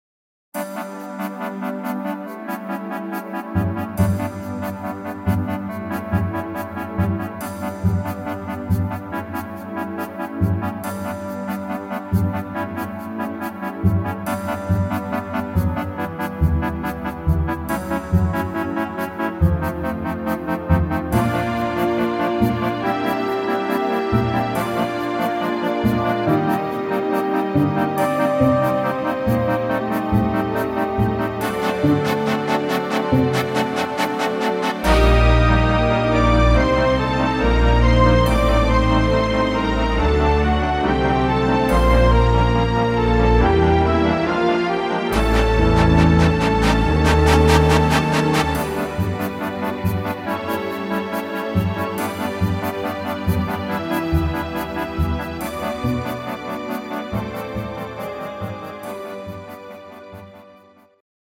Musicalversion (instr.)